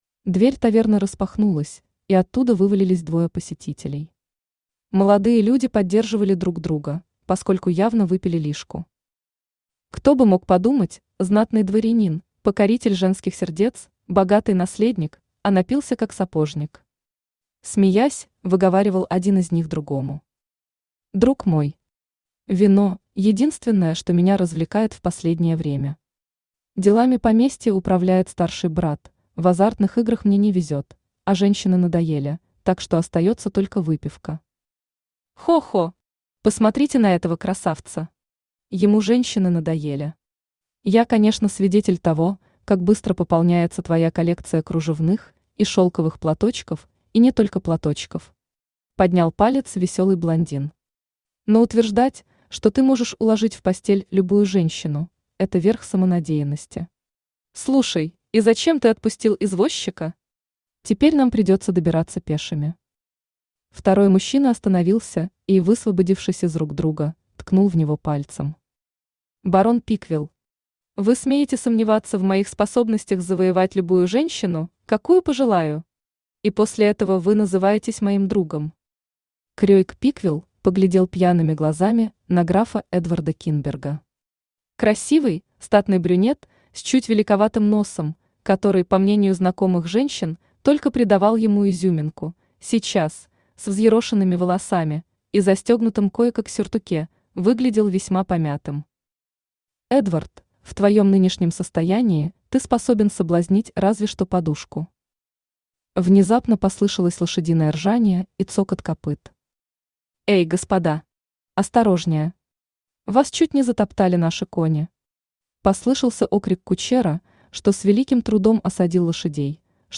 Аудиокнига Никто, кроме неё | Библиотека аудиокниг
Aудиокнига Никто, кроме неё Автор Lyana Seamens Читает аудиокнигу Авточтец ЛитРес.